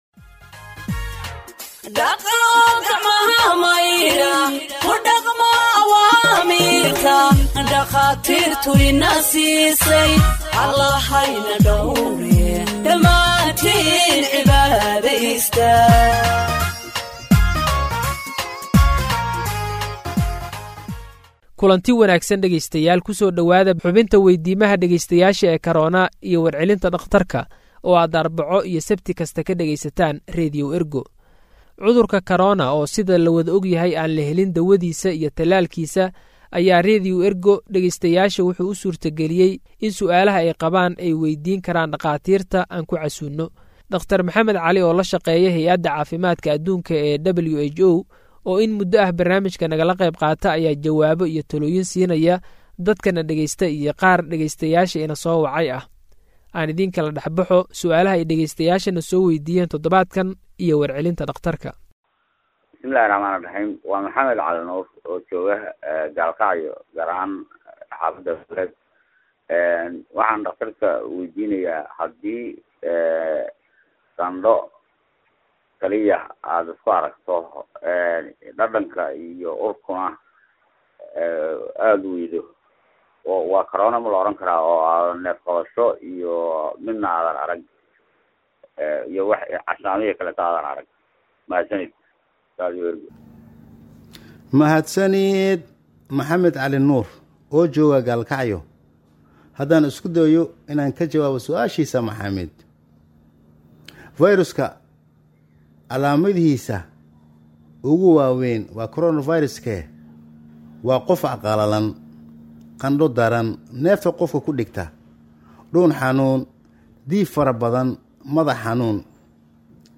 Health expert answers listeners’ questions on COVID 19 (35)